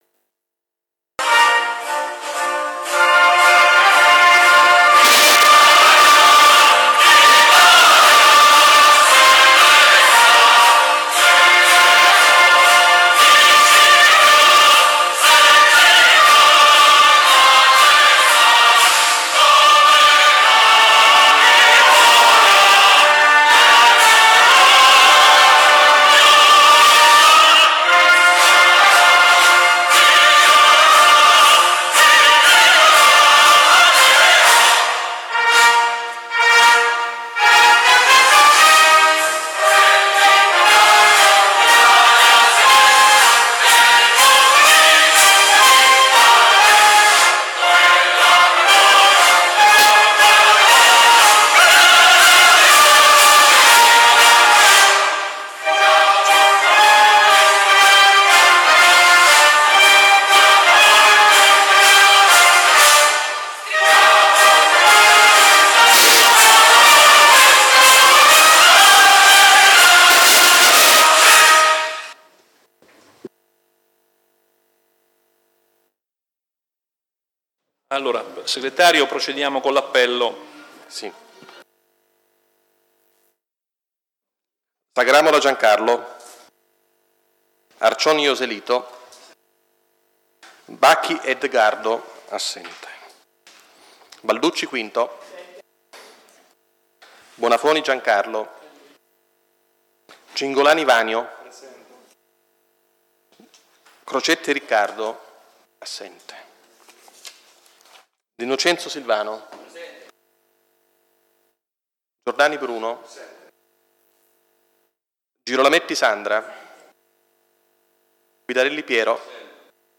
Ai sensi dell'art. 20, comma 7, dello Statuto Comunale e dell'articolo 14 del regolamento consiliare, il Consiglio Comunale è convocato martedì 6 giugno alle ore 18 presso la sala Consiliare dell'Unione Montana dell'Esino-Frasassi in via Dante n.268 a Fabriano